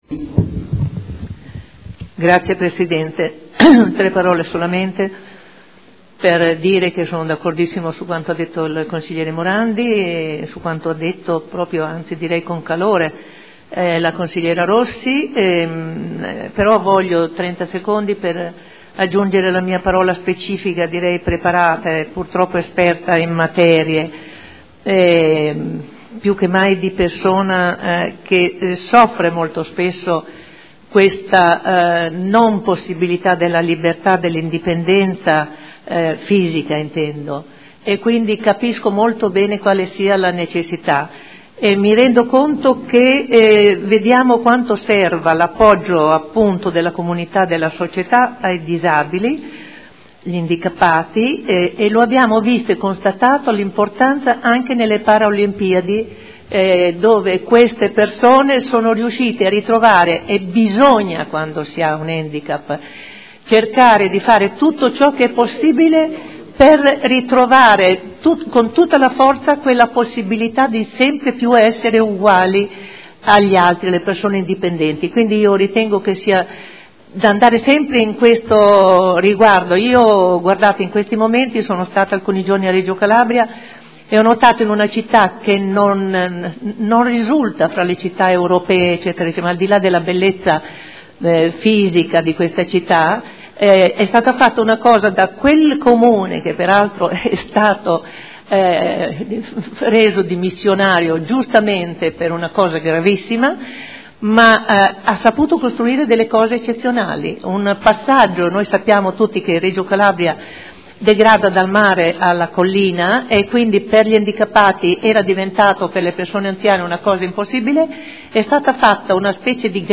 Seduta del 05/11/2012. Dibattito su proposta di deliberazione: Linee di indirizzo per l’affidamento del servizio di trasporto disabili alle attività diurne, socio-occupazionali e del tempo libero – Periodo dal 1.3.2013 al 28.2.2015